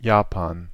Ääntäminen
IPA : /dʒəˈpæn/